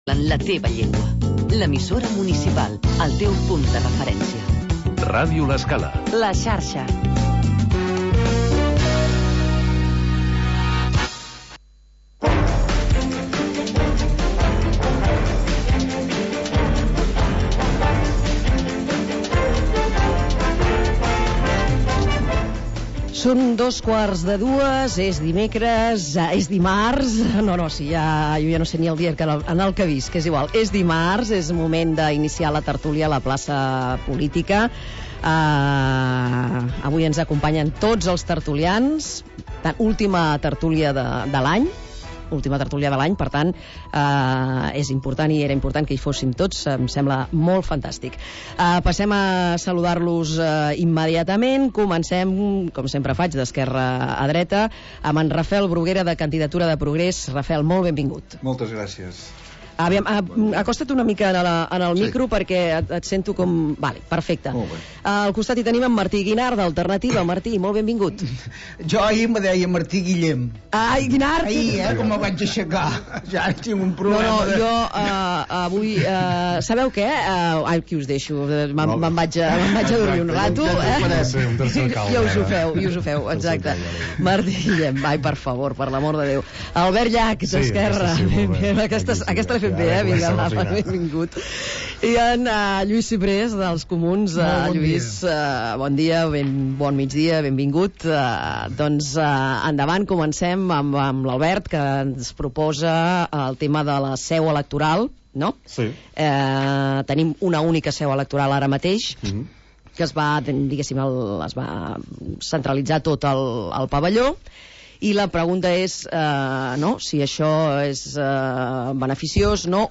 Tertúlia de caire polític